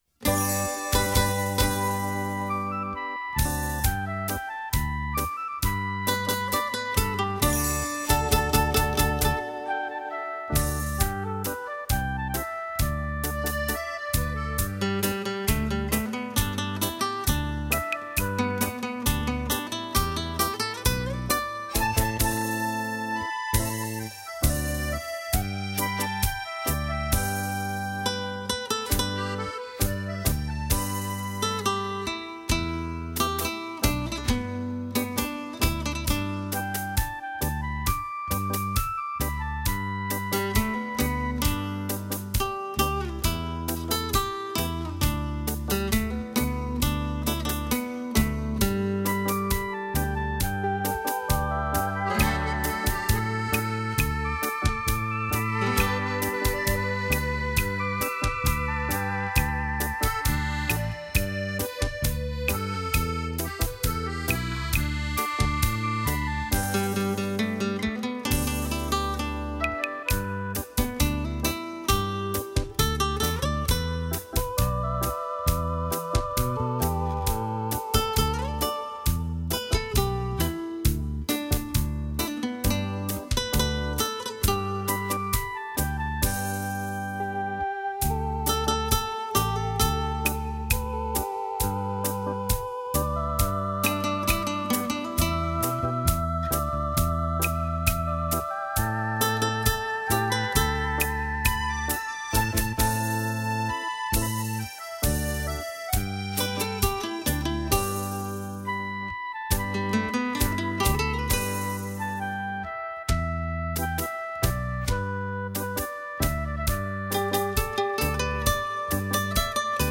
手风琴演奏